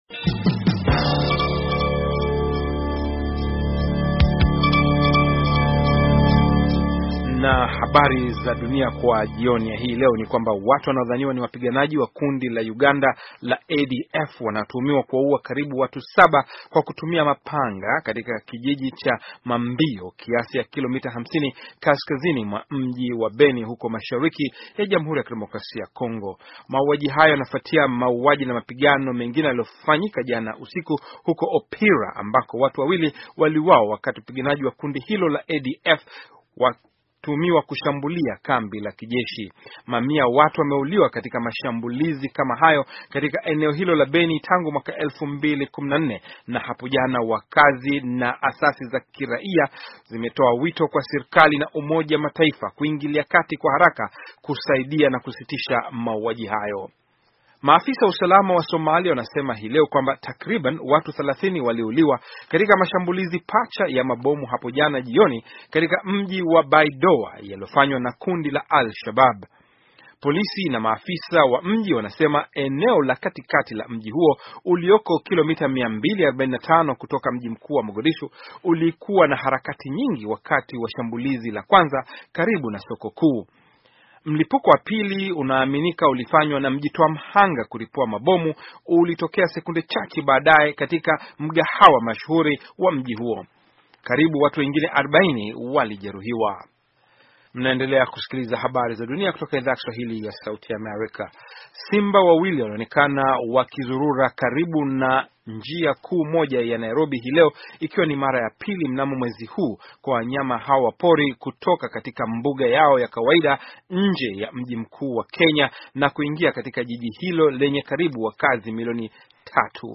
Taarifa ya habari - 4:30